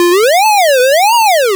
retro_synth_wobble_04.wav